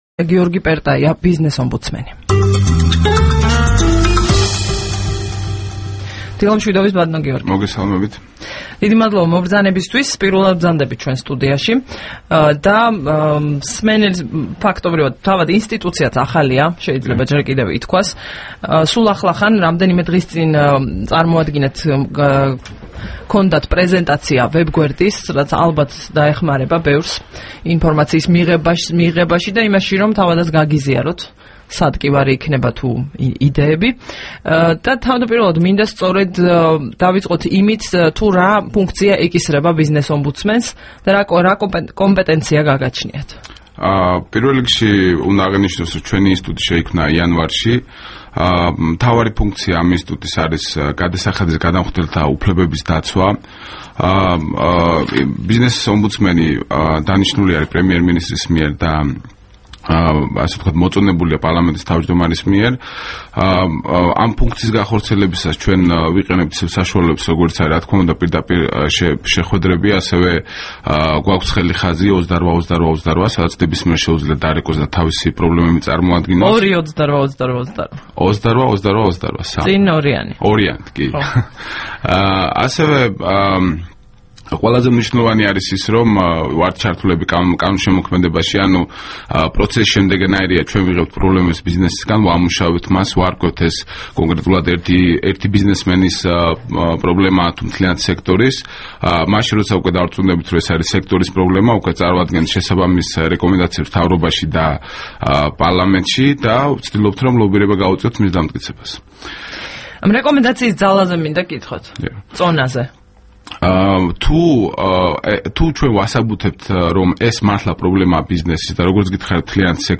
24 ნოემბერს რადიო თავისუფლების დილის გადაცემის სტუმარი იყო გიორგი პერტაია, ბიზნესომბუდსმენი.
საუბარი გიორგი პერტაიასთან